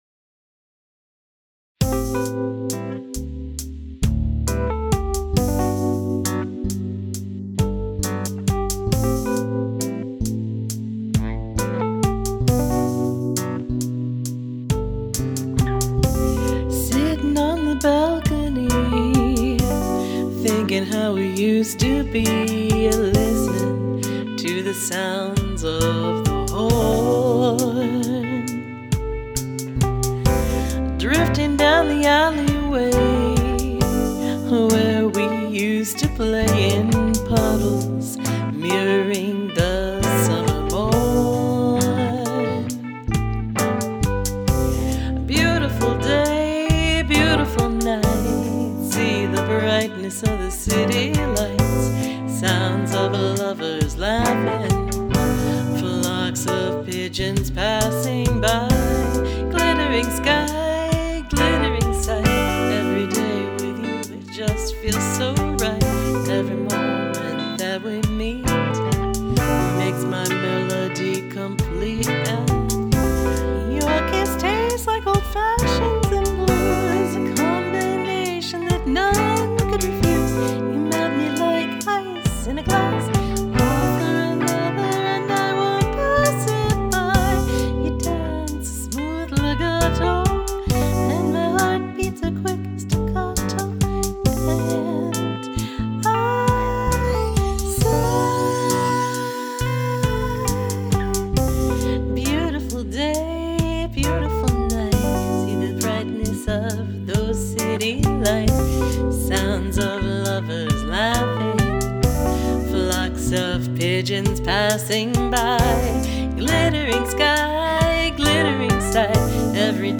This track was revised on 01/12/2023, adjusted vocal levels, and added rough concept bass; original is at the bottom)